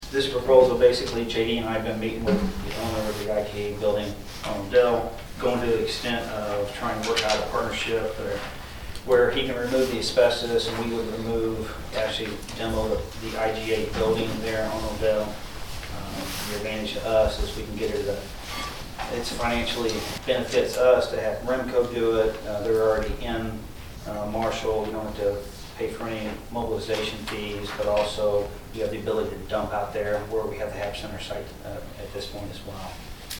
During the meeting of the city council on Monday, December 6, Ward 2 Councilman Kirk Arends said he thinks while REMCO Demolition LLC is in town wrapping up its demolition of the buildings on the property formerly known as the Marshall Habilitation Center, it is a good time to lock in a deal for the demolition of the old IGA building.